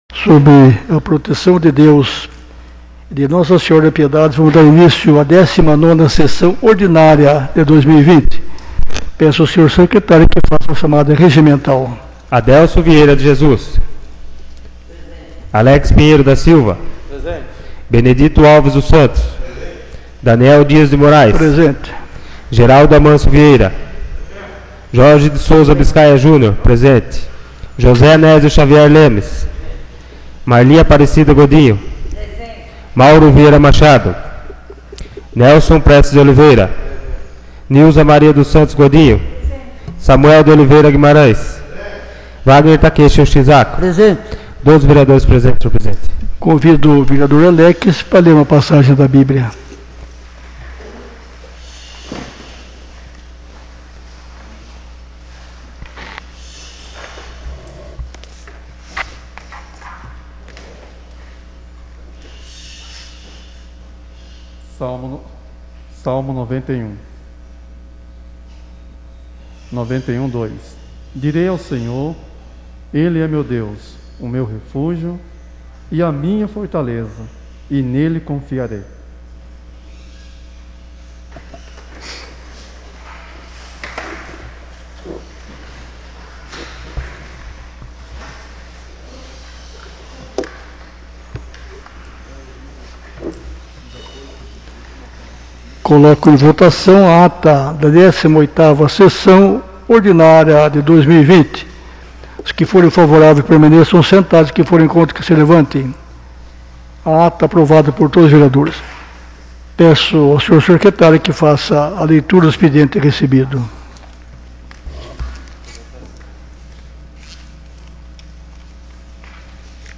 19ª Sessão Ordinária de 2020